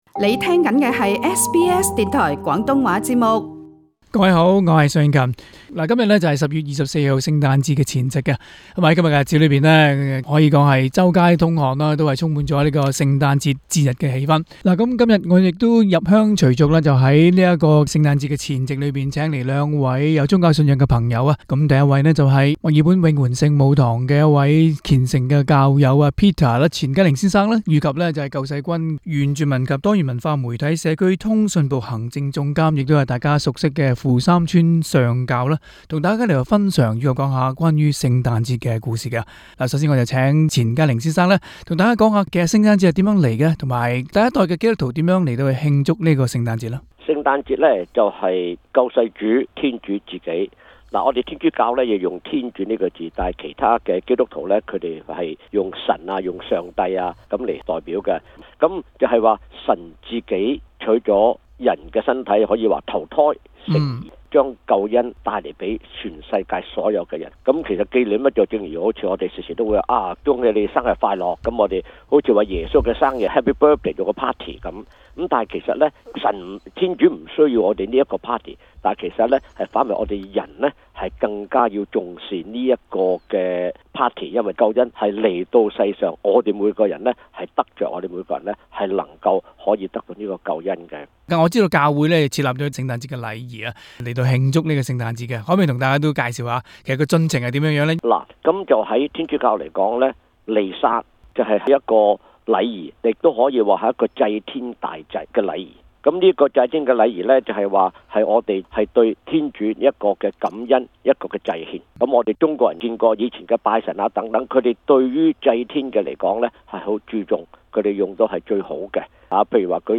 今期【社區專訪】環節與大家一同探討宗教人如何慶祝聖誕節？